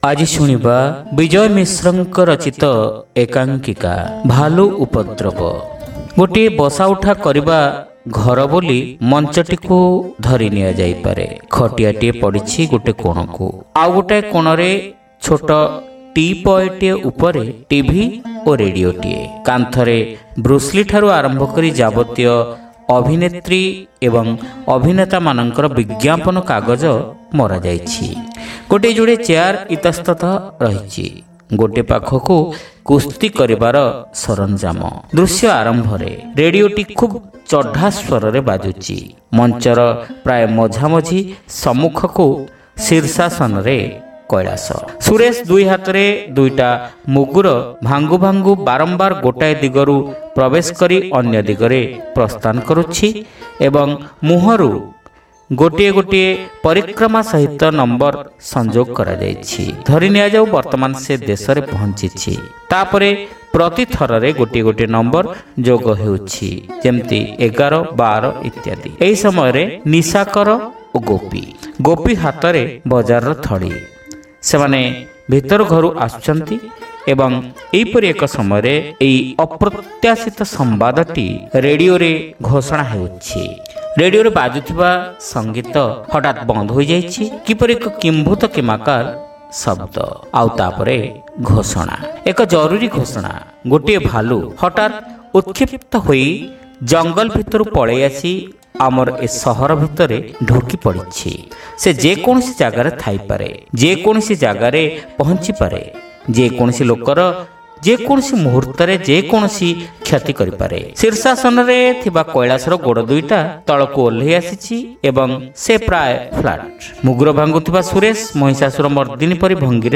ଶ୍ରାବ୍ୟ ଏକାଙ୍କିକା : ଭାଲୁ ଉପଦ୍ରବ (ପ୍ରଥମ ଭାଗ)